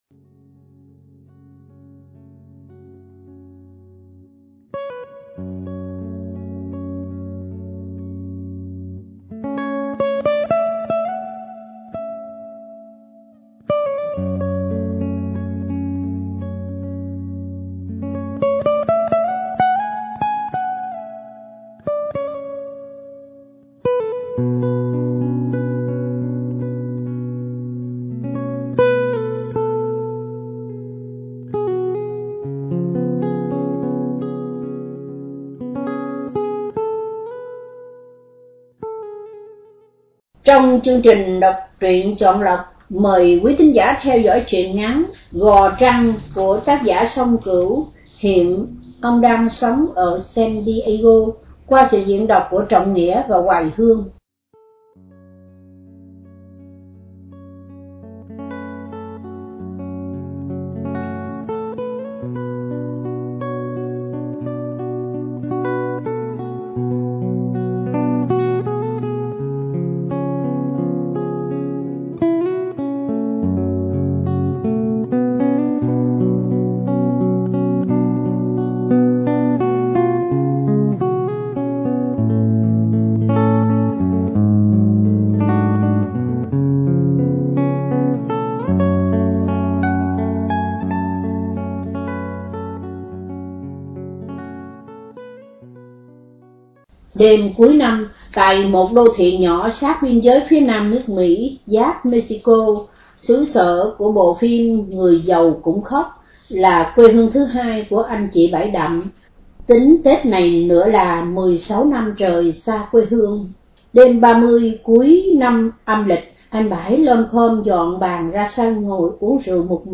Đọc Truyện Chọn Lọc – Truyện Ngắn Gò Trăng – Tác Giả Sông Cửu – Radio Tiếng Nước Tôi San Diego